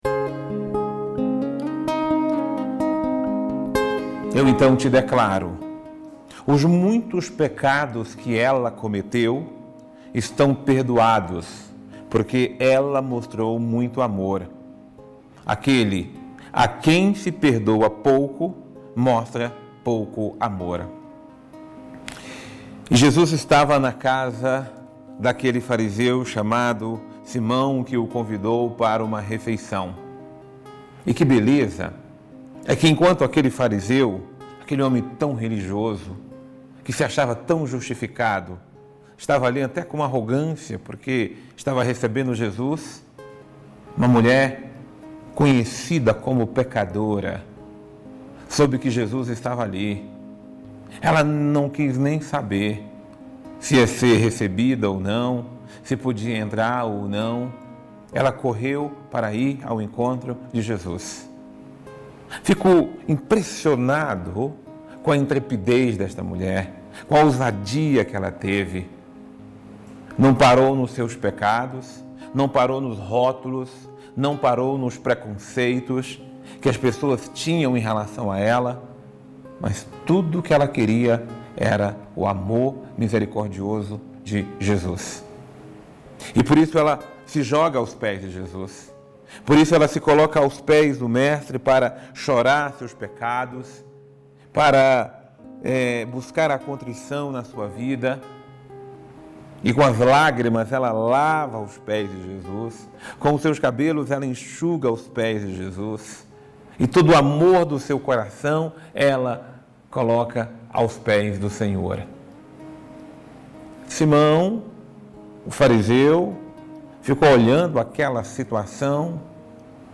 Homilia diária | O lugar dos pecadores é aos pés de Jesus